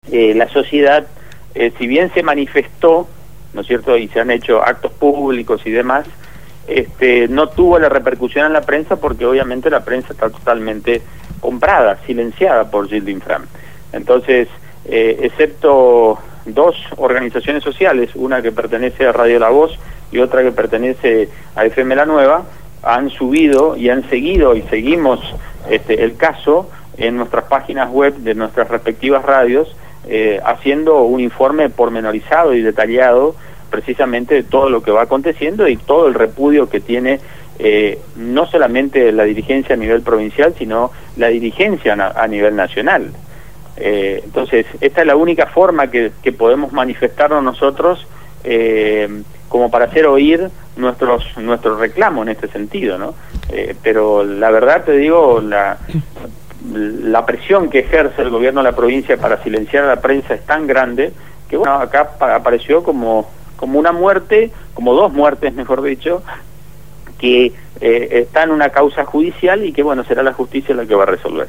entrevistaron